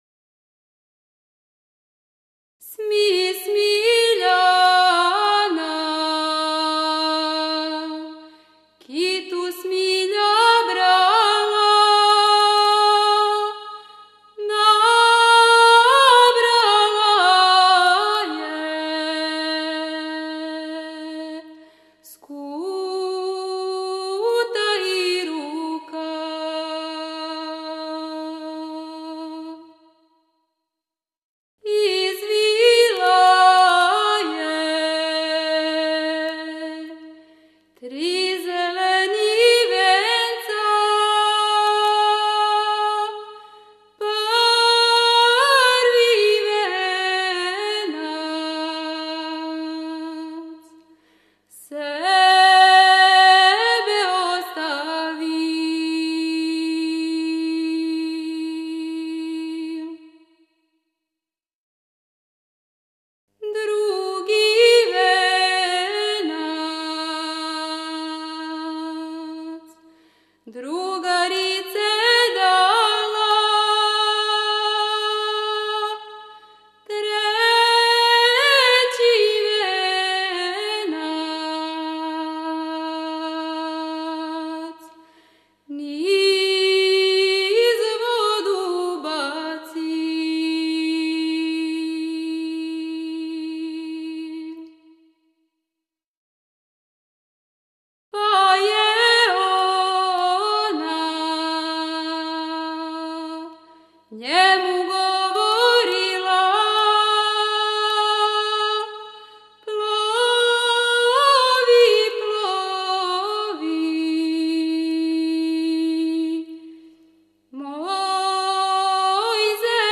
Снимци Етномузиколошки одсек Музичке школе "Мокрањац", Београд (2.9 MB, MP3) О извођачу Албум Уколико знате стихове ове песме, молимо Вас да нам их пошаљете . Порекло песме: Село Сумраковац, околина Бољевца Начин певања: ? Напомена: Ђурђевданска песма